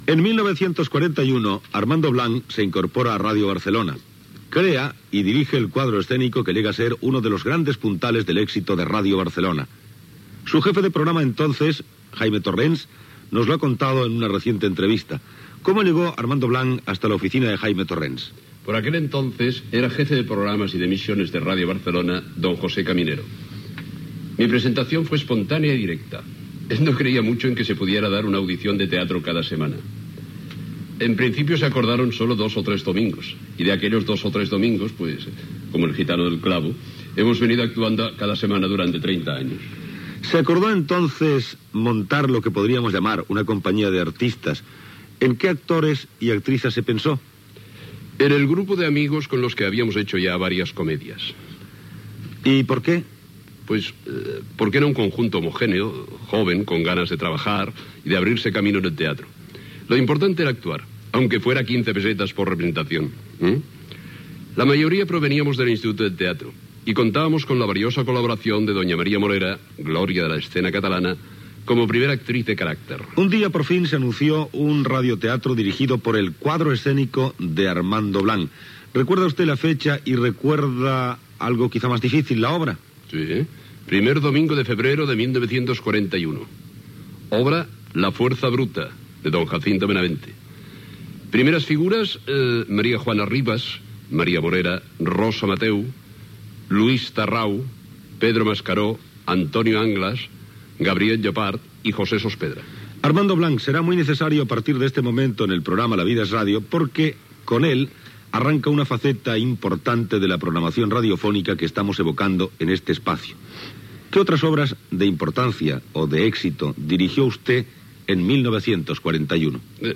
Entrevista
Entreteniment